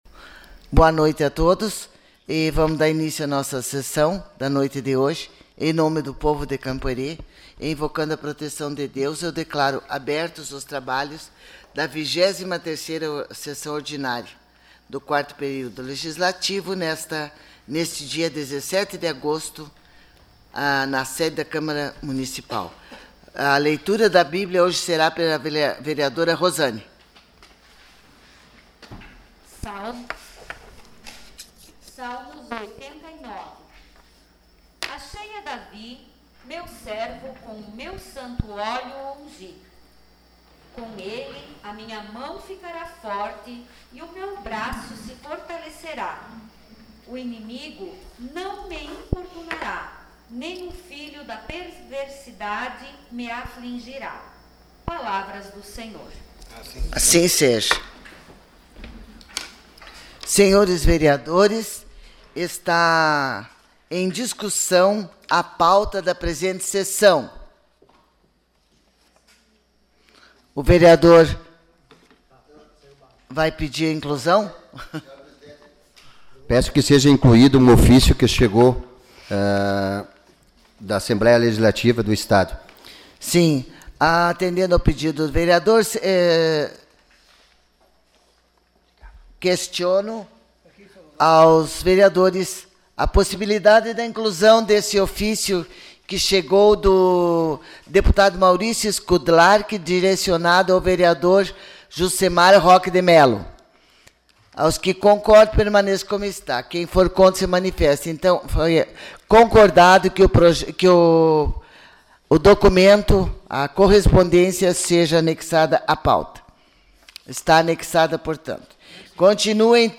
Sessão Ordinária dia 17 de agosto de 2020